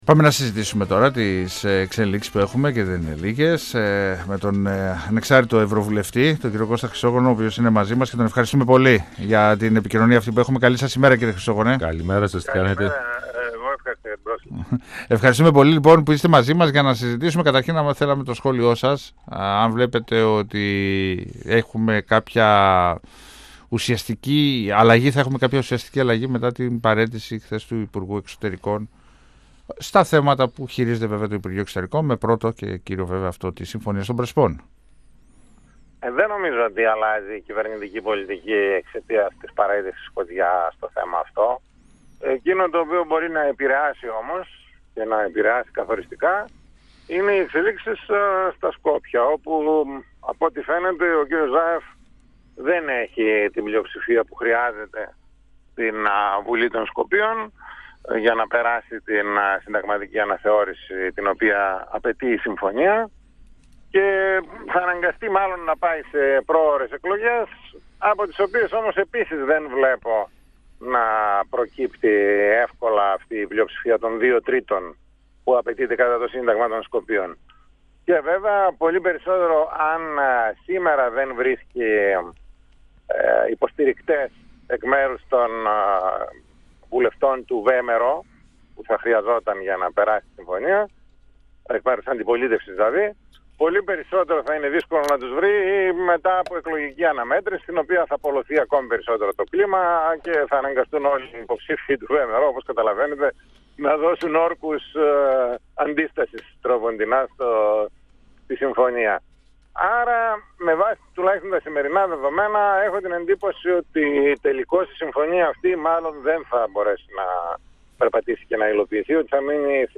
Δεν θα υπάρξει αλλαγή στην εξωτερική πολιτική της χώρας μας μετά την παραίτηση του Νίκου Κοτζιά από το υπουργείο Εξωτερικών εκτίμησε ο ανεξάρτητος Ευρωβουλευτής, Κώστας Χρυσόγονος, μιλώντας στον 102fm του Ραδιοφωνικού Σταθμού Μακεδονίας της ΕΡΤ3. Ο κ. Χρυσόγονος πρόσθεσε ότι στο θέμα της Συμφωνίας των Πρεσπών, τυχόν εξελίξεις θα σημειωθούν λόγω της κατάστασης που διαμορφώνεται στην πΓΔΜ.
Ο κ. Χρυσόγονος πρόσθεσε ότι στο θέμα της Συμφωνίας των Πρεσπών, τυχόν εξελίξεις θα σημειωθούν λόγω της κατάστασης που διαμορφώνεται στην πΓΔΜ. 102FM Συνεντεύξεις ΕΡΤ3